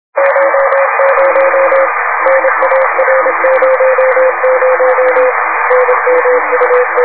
Signaux entendus sur RS13, bande 2m, le 05/05/2002 vers 2045 UTC :
la balise et la télémétrie -